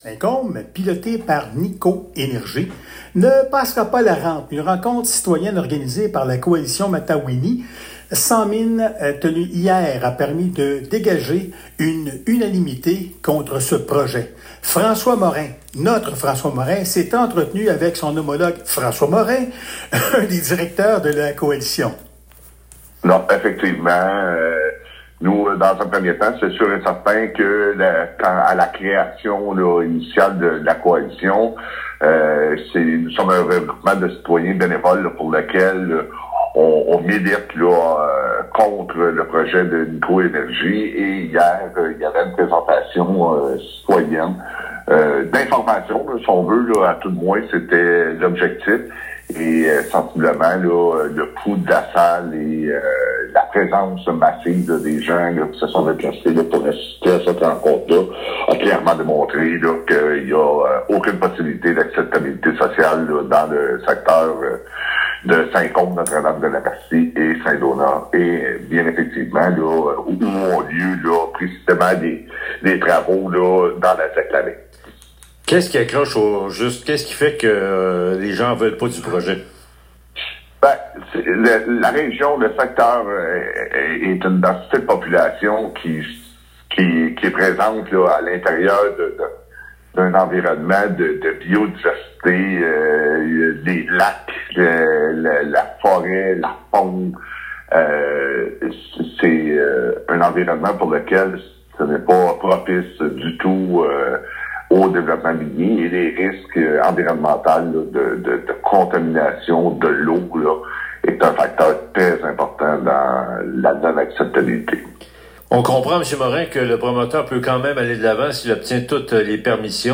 Entrevue à la radio CFNJ